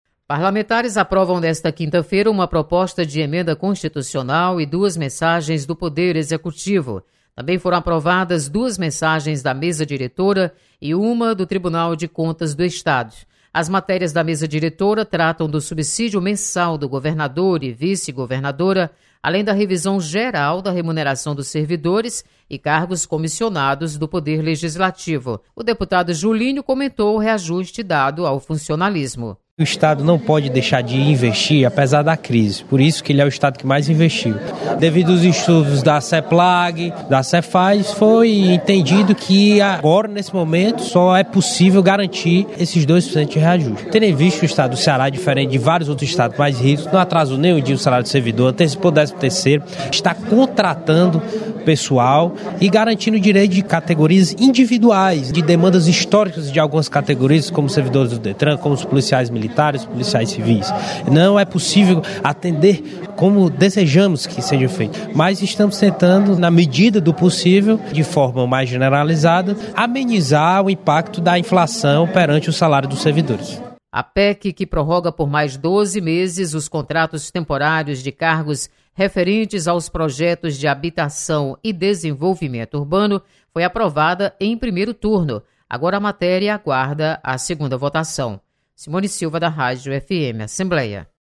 Você está aqui: Início Comunicação Rádio FM Assembleia Notícias Plenário